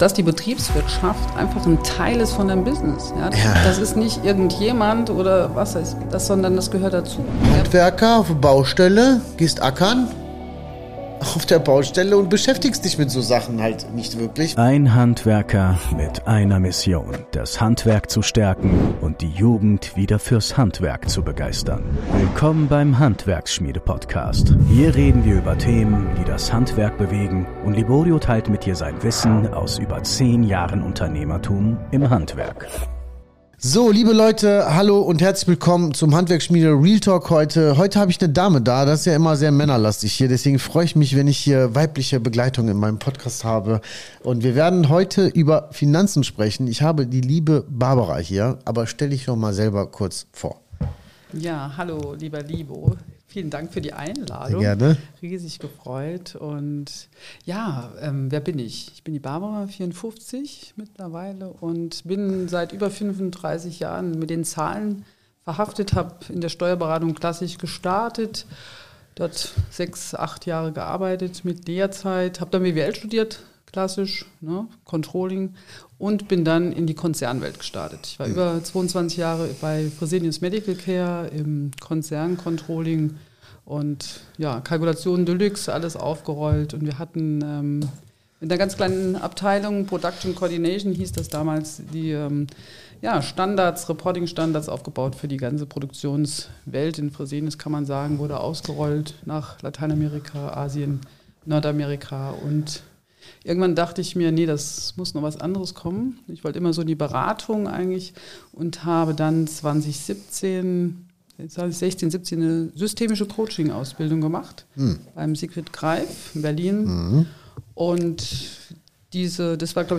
Wegen diesen 3 Fehlern gehen Handwerker pleite | Interview